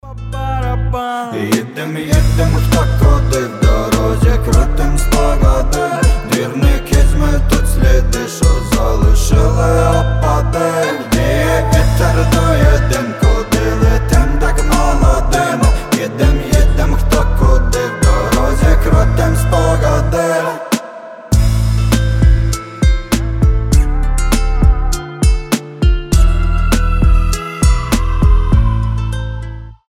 • Качество: 320, Stereo
украинские
дорожные